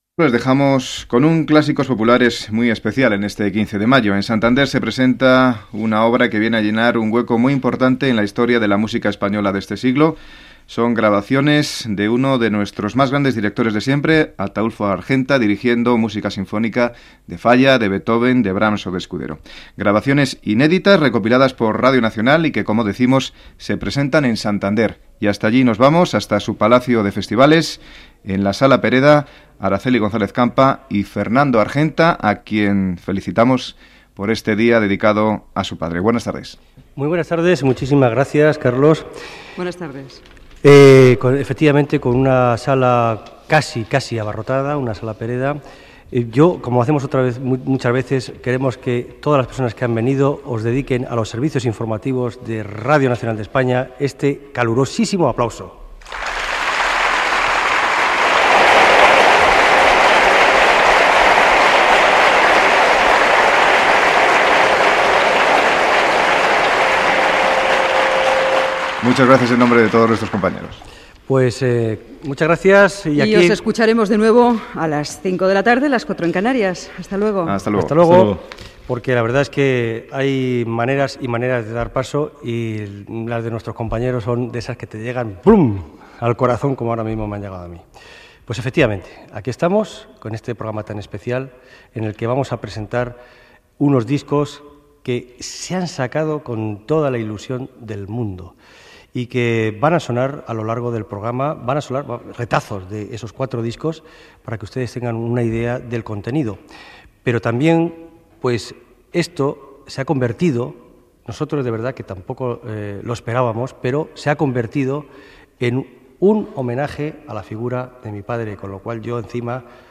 Espai fet des de Santander dedicat al director d'orquestra Ataulfo Argenta. Aplaudiment inicial, presentació, tema musical, comentari sobre els discos del director que s'han publicat i tema musical
Musical